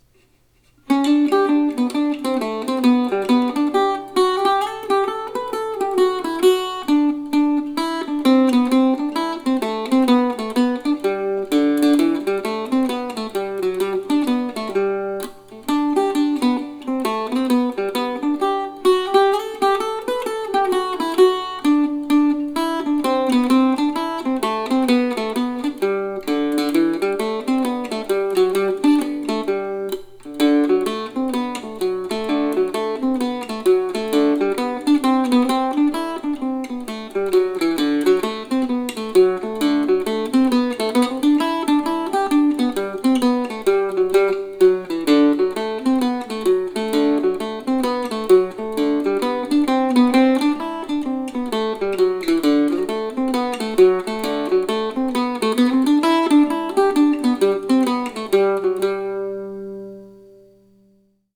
for mandocello or octave mandolin.